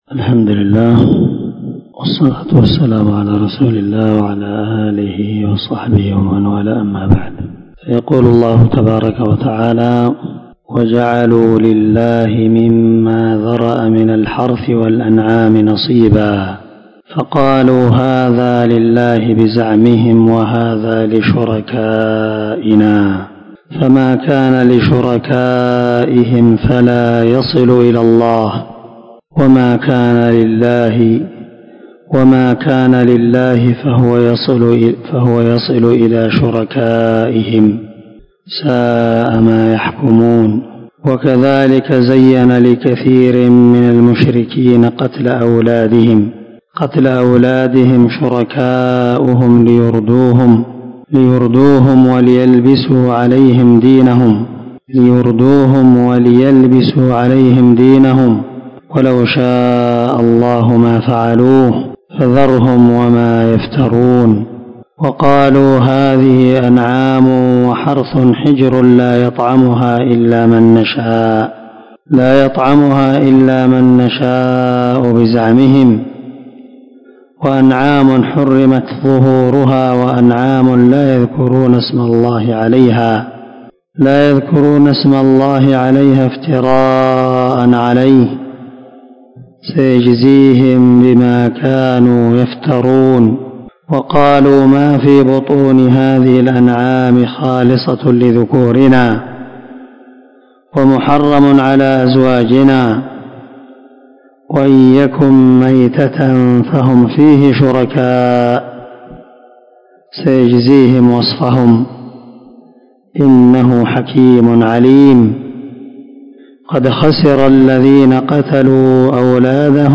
437الدرس 45 تفسير آية ( 136 – 140 ) من سورة الأنعام من تفسير القران الكريم مع قراءة لتفسير السعدي